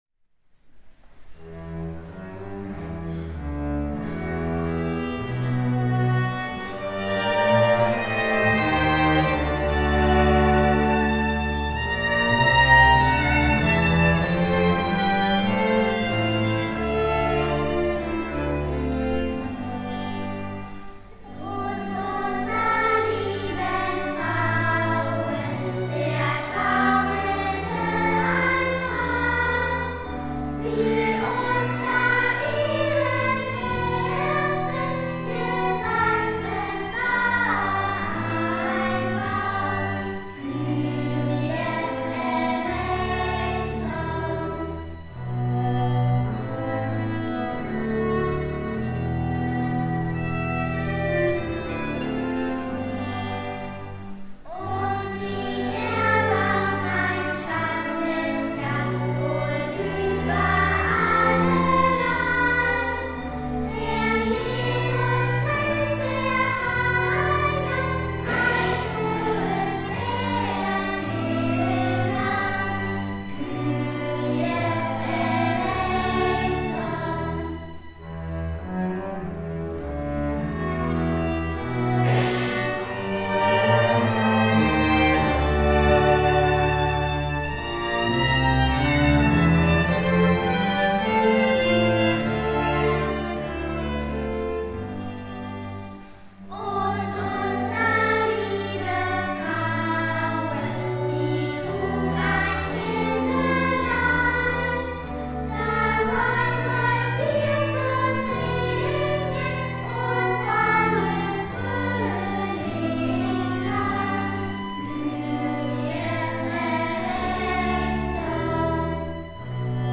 Kinderchor
3 Marienlieder (gemeinsam mit dem Jugendchor)
Drei-Marienlieder.mp3